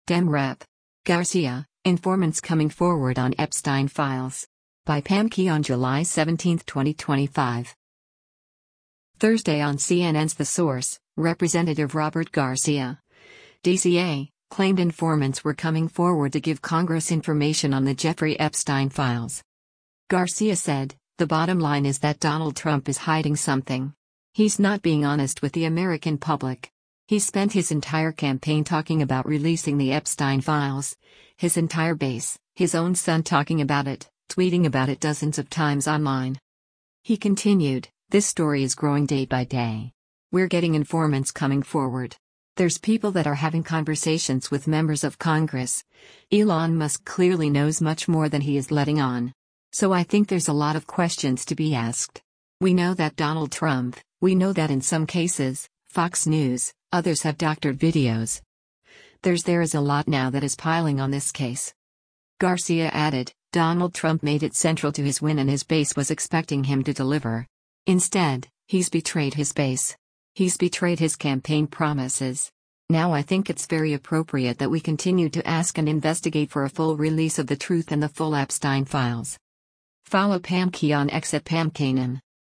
Thursday on CNN’s “The Source,” Rep. Robert Garcia (D-CA) claimed “informants” were coming forward to give Congress information on the Jeffrey Epstein files.